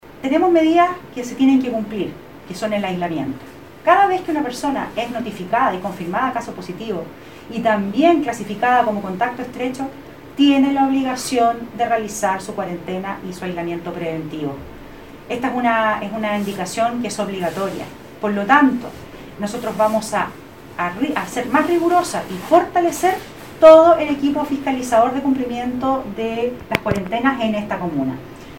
La Seremi de Salud, Scarlett Molt, se refirió a las medidas a cumplir enfatizando en el aislamiento.